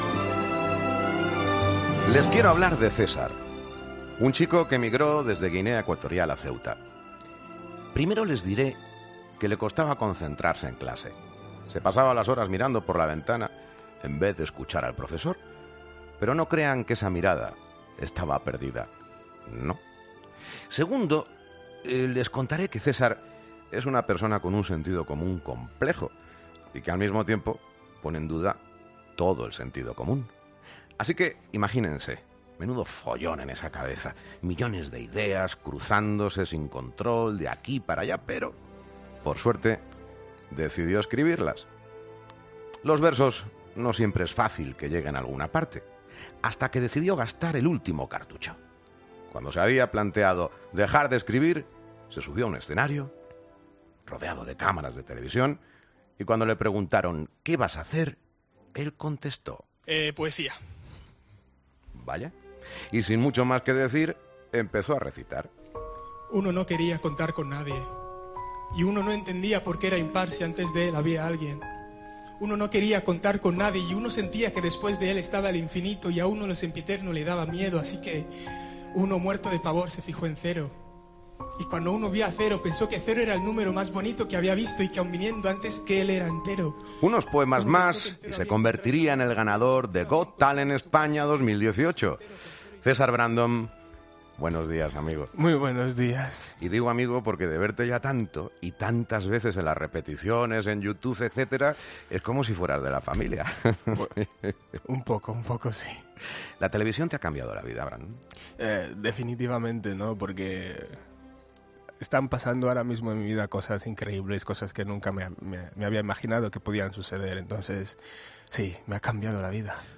Escucha la entrevista a César Brandon en Herrera en COPE